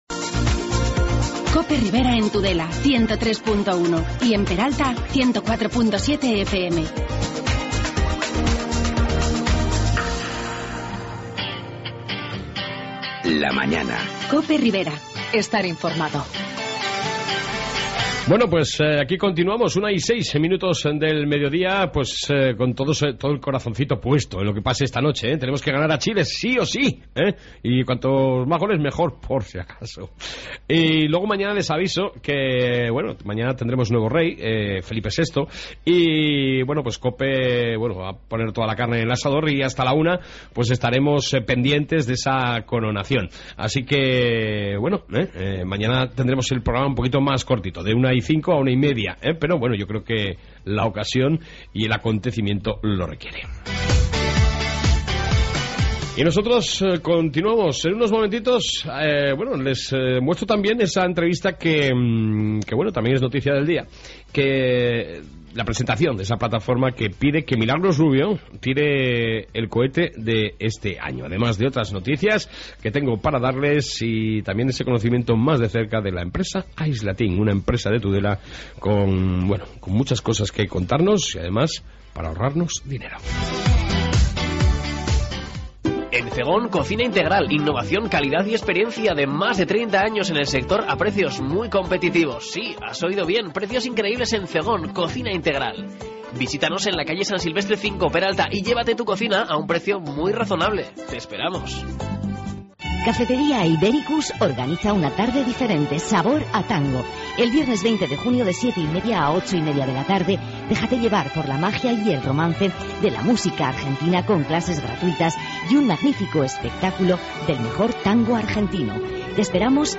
AUDIO: en esta 2 parte entrevista con la plataforma "Que lo tire Mila" y con la empresa Tudelana Aislateam